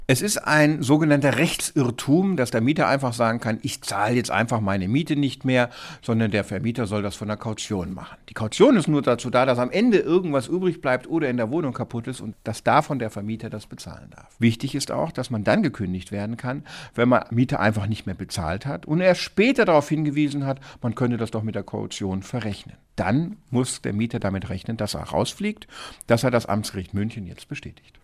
O-Töne / Radiobeiträge, , , , , ,
Rechtsanwalt